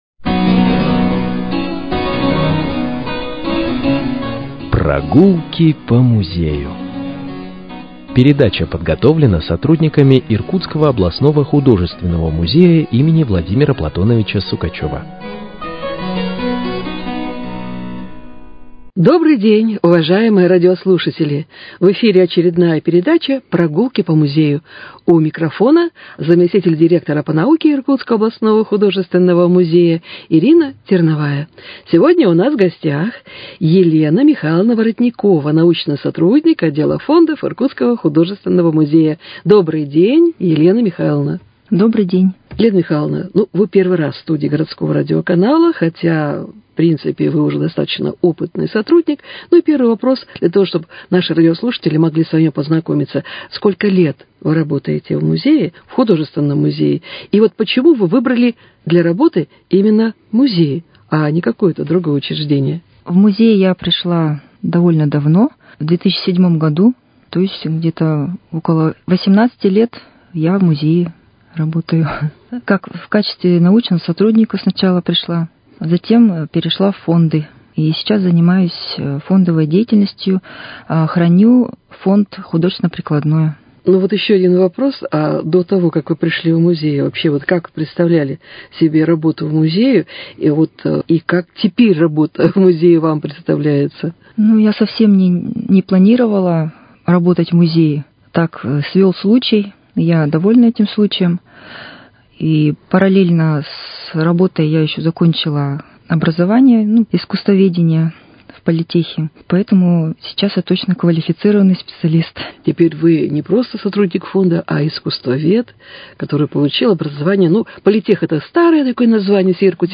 Прогулки по музею: Беседа с искусствоведом